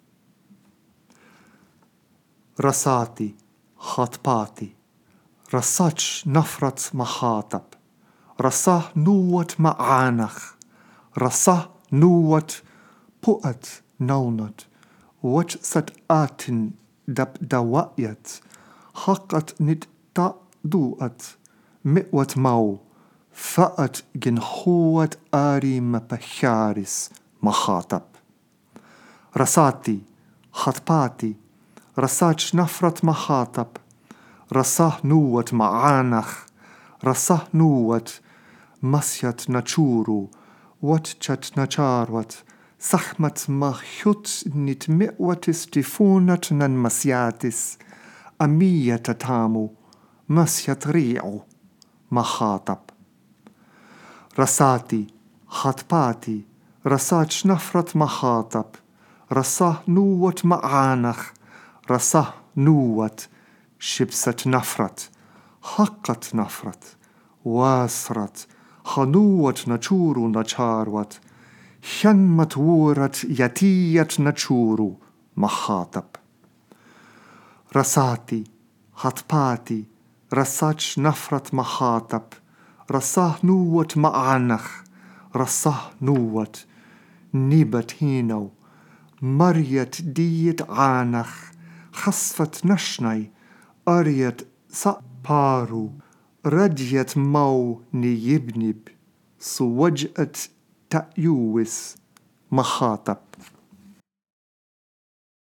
If you want to hear how the Hymn might have sounded in Egyptian, I have recorded it here: